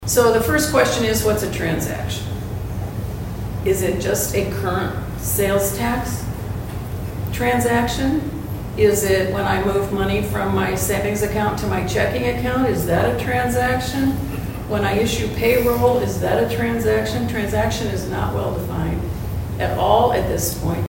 ABERDEEN, S.D.(HubCityRadio)- The Aberdeen Chamber of Commerce’s Chamber Connections Series continue Thursday at the K.O.Lee Public Library.